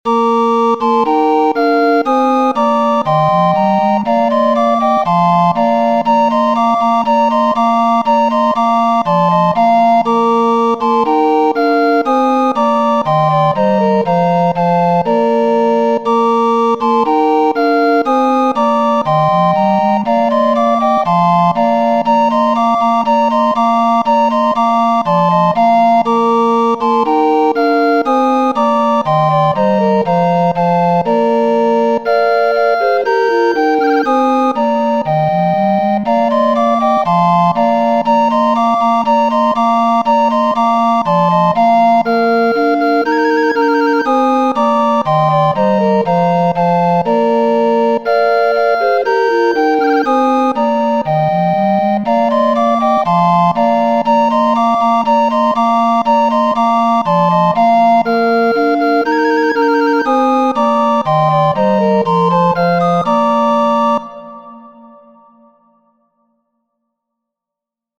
bransle.mp3